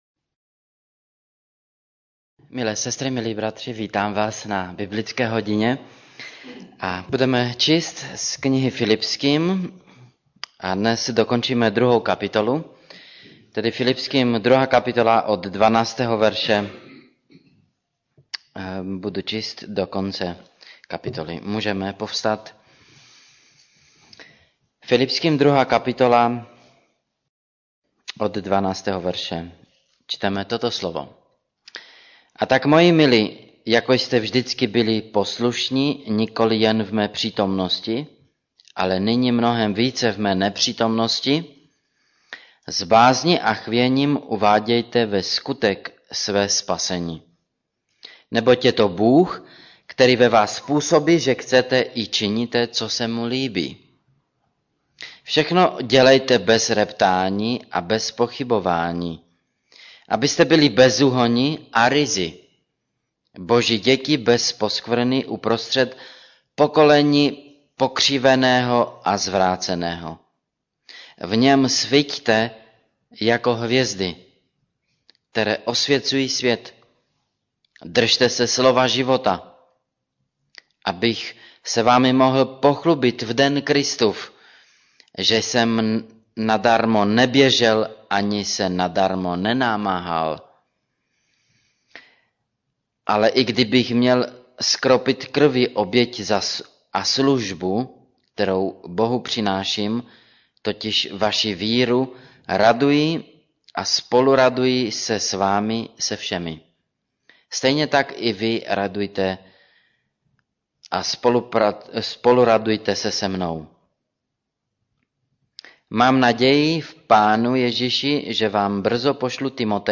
Radost ze služby Kategorie: Kázání MP3 Zobrazení: 3165 Jak žít radostný život 4.